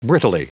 Prononciation du mot brittlely en anglais (fichier audio)
Prononciation du mot : brittlely